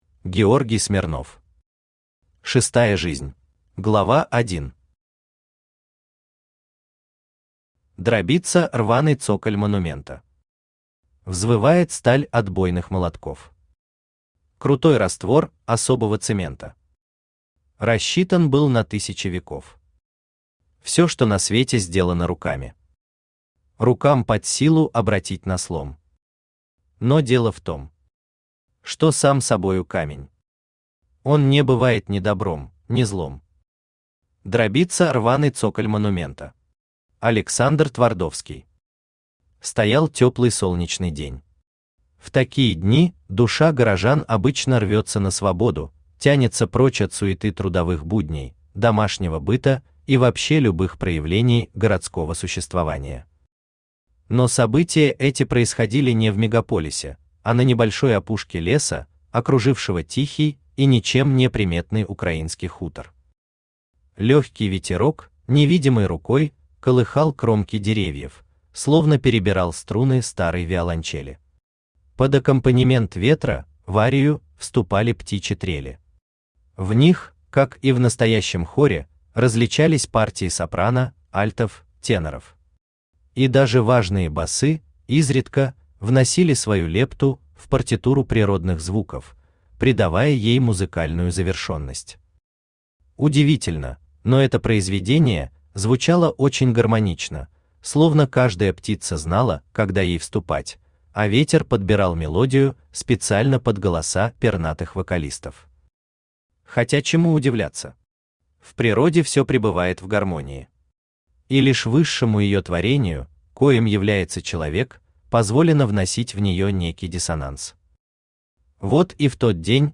Аудиокнига Шестая жизнь | Библиотека аудиокниг
Aудиокнига Шестая жизнь Автор Георгий Константинович Смирнов Читает аудиокнигу Авточтец ЛитРес.